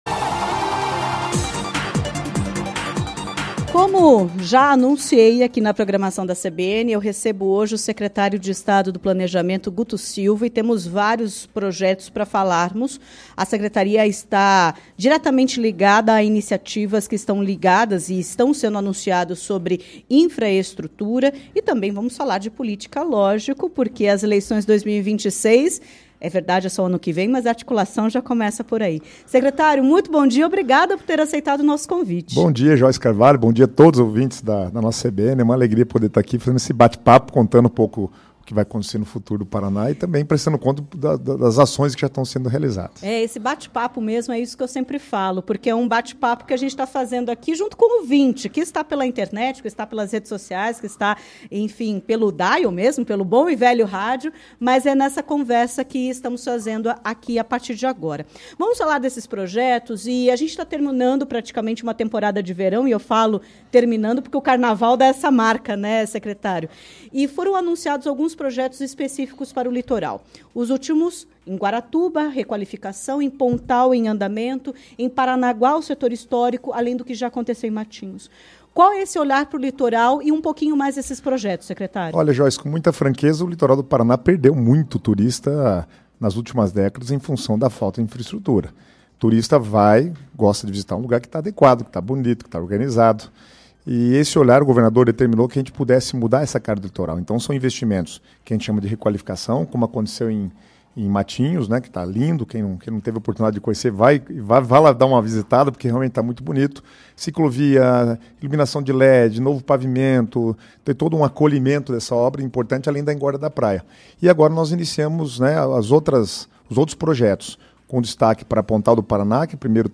Em entrevista à CBN Curitiba nesta quinta-feira (06), o secretário de Estado do Planejamento, Guto Silva, comentou sobre projetos de infraestrutura do Estado, como a Ponte de Guaratuba. De acordo com ele, depois da liberação da ponte – com entrega prevista em abril de 2026 -, haverá uma readequação do trânsito na região diante da nova realidade.